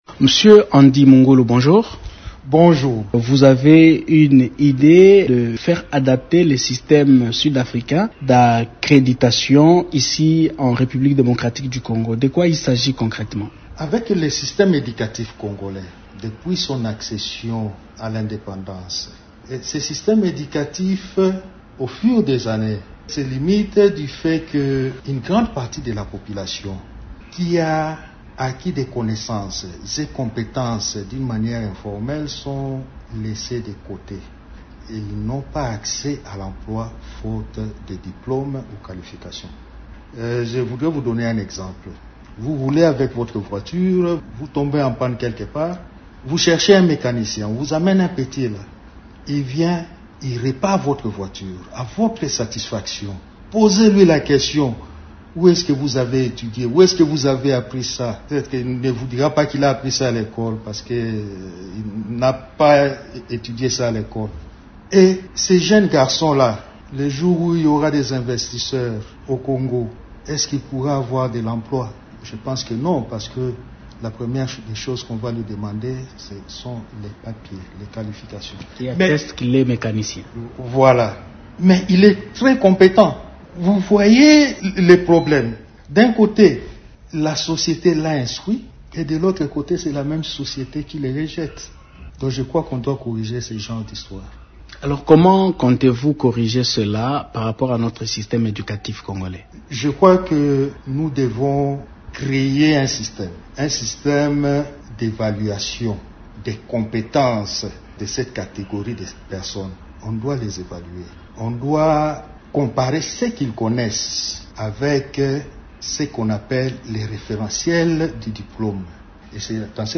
Ce professeur d'université a fait cette proposition, ce mardi, au cours d'un entretien accordé à Radio okapi.
est interrogé par